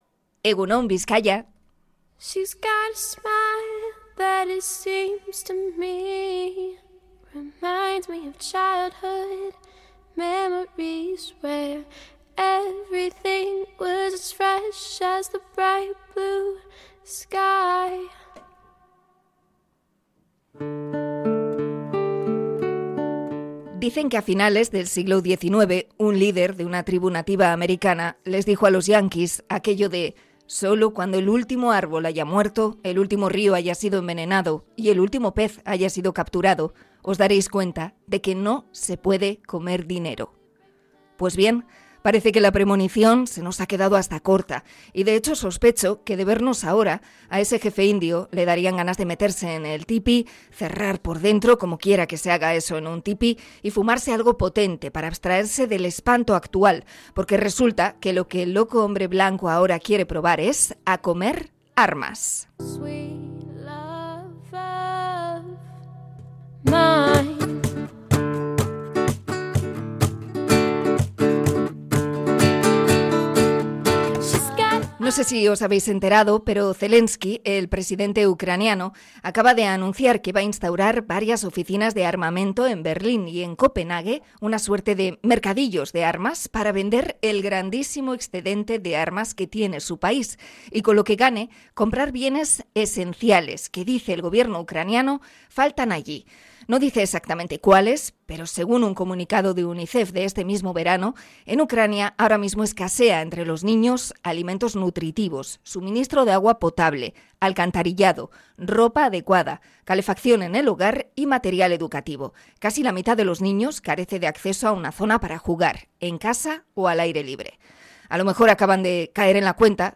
Comentario sobre la industria armamentística